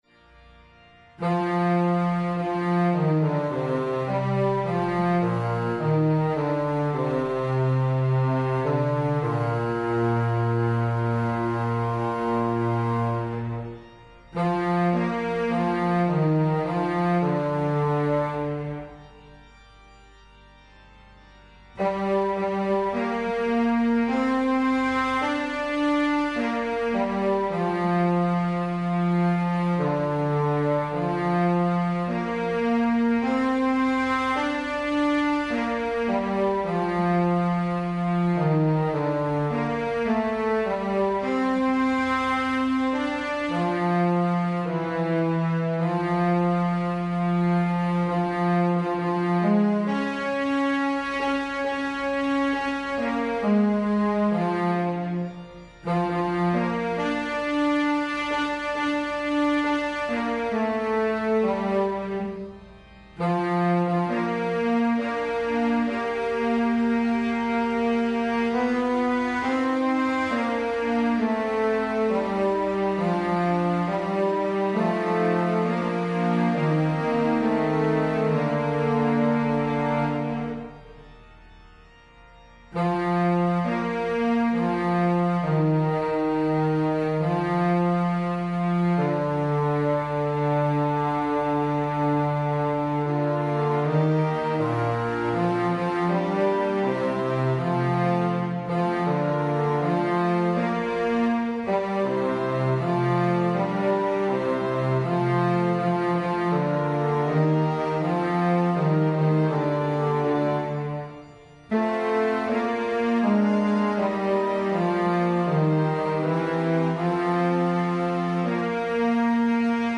Bass
Evensong Setting